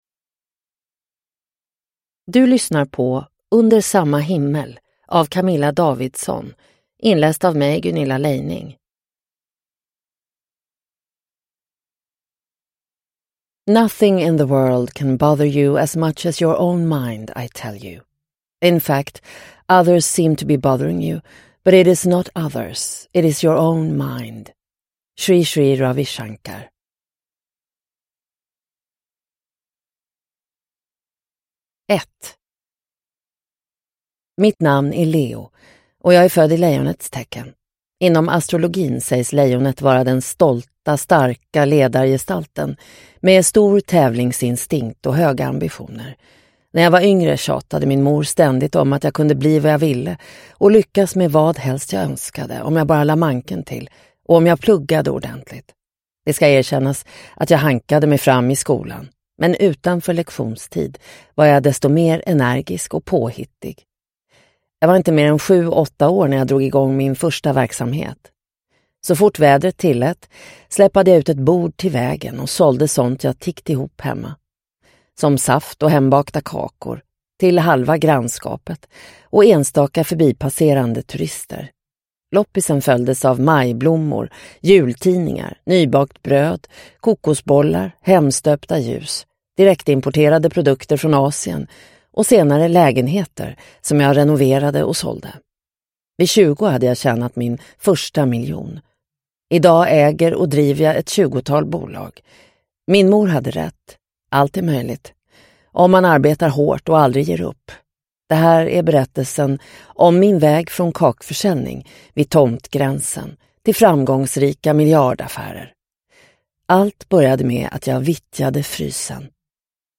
Under samma himmel – Ljudbok – Laddas ner